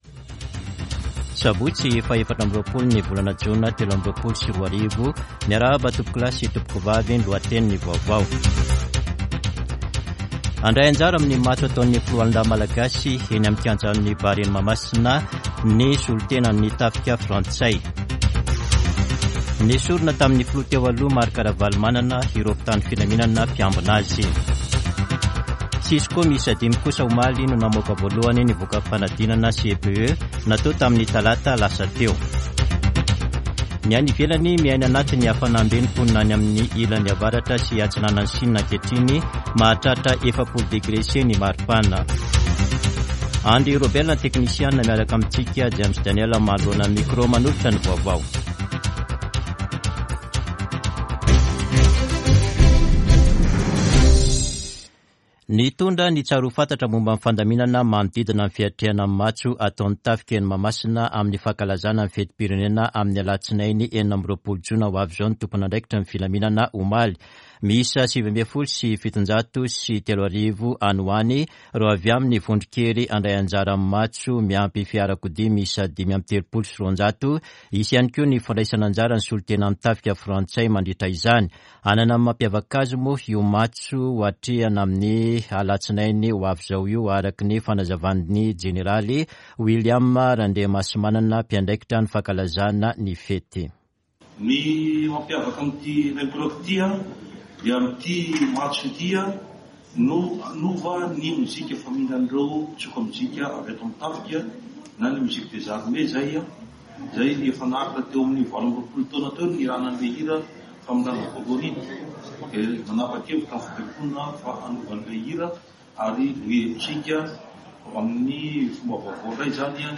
[Vaovao maraina] Sabotsy 24 jona 2023